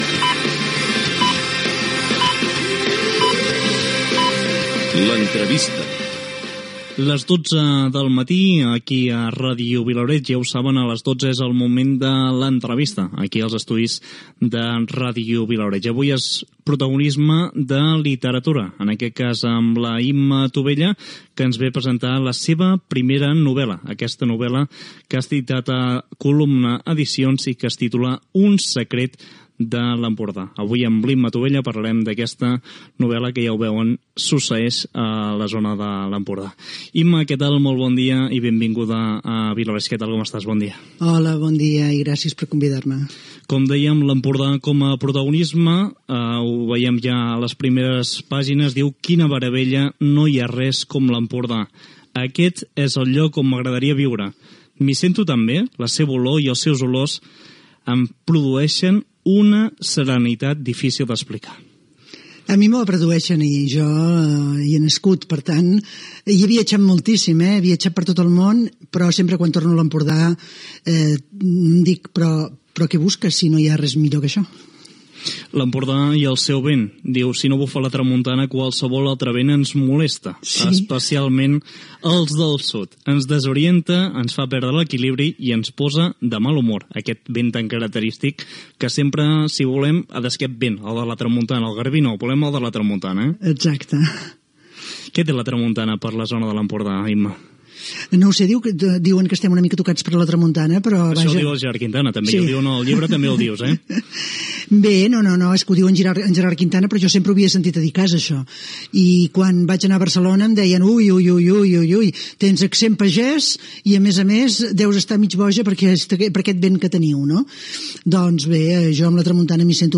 Careta del programa, hora, identificació de l'emissora